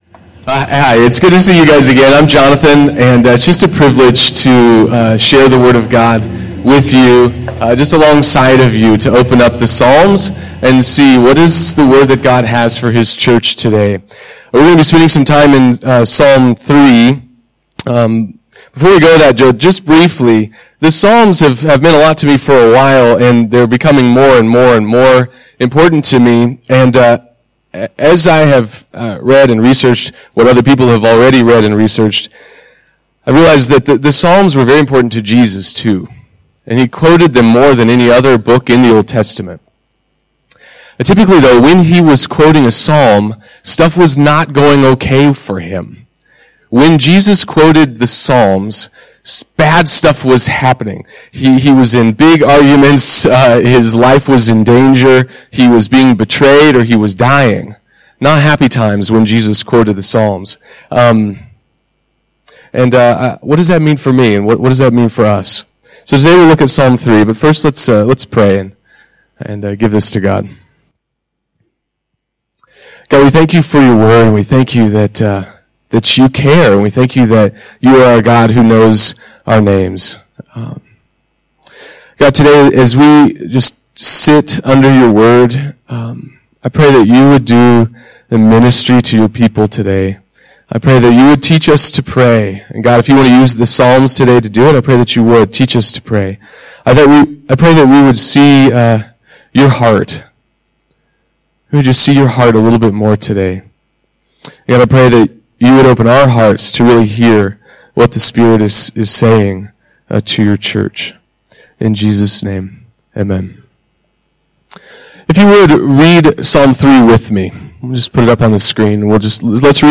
A walk through the historical context and present implications of Psalm 3. Recorded at the International Christian Assembly, Phnom Penh, Cambodia, July 2016.